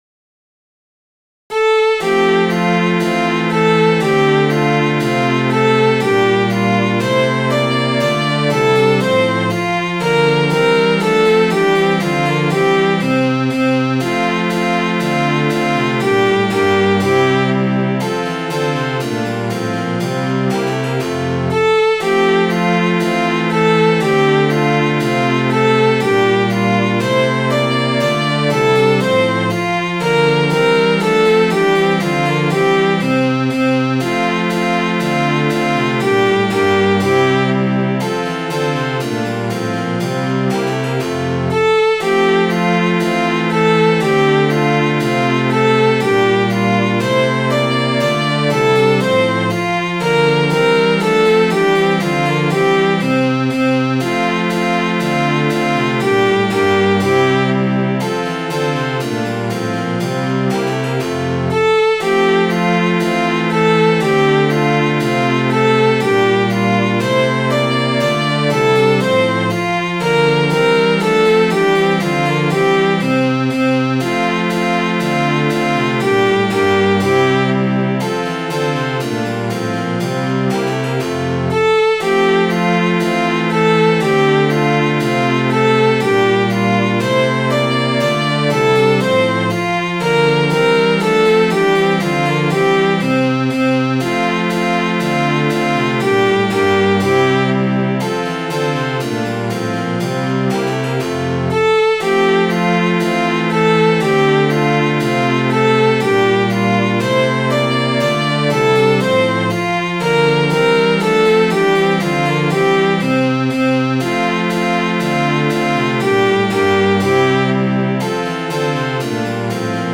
Midi File, Lyrics and Information to The Deceived Girl